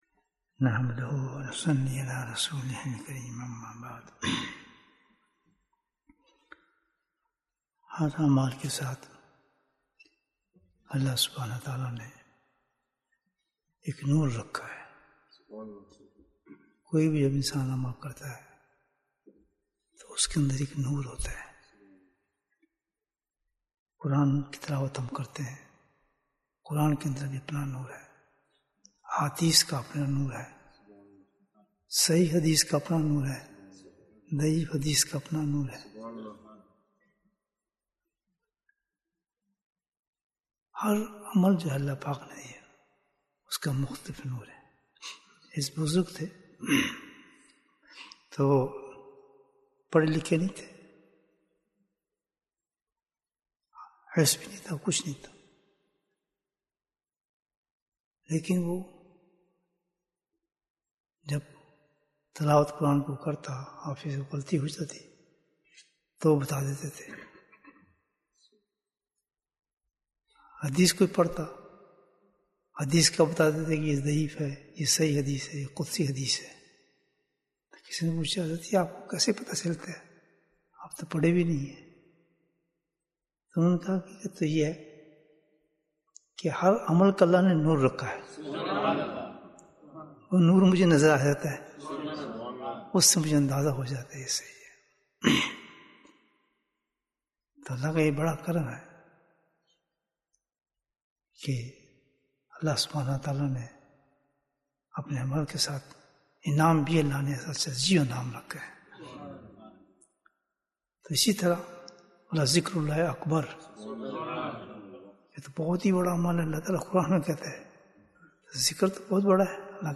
ذکر کا نور Bayan, 26 minutes13th September, 2022